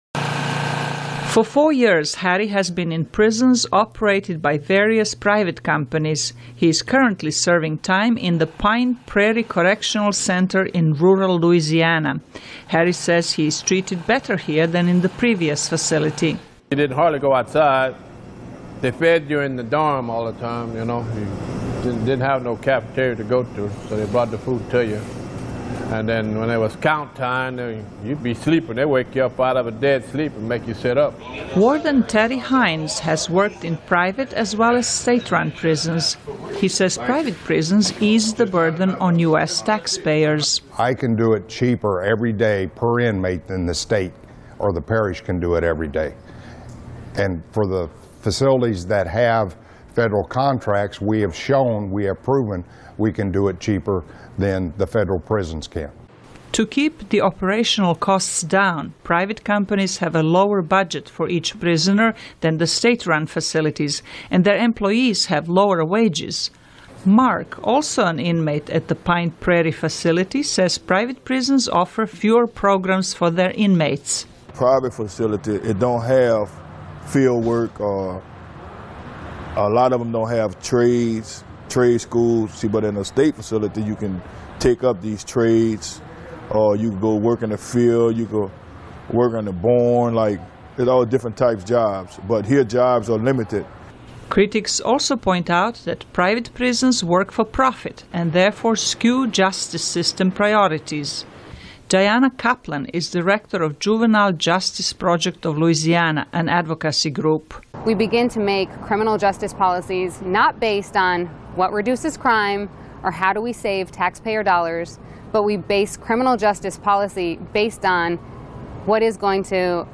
美国依靠私营监狱降低费用(VOA视频)